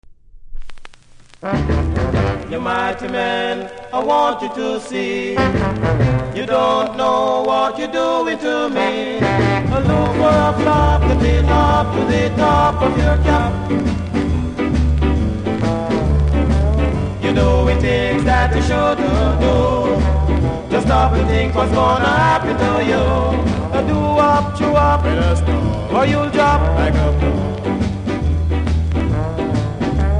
キズ、摩耗多めですが多少のノイズが気にならない人ならプレイ可レベル。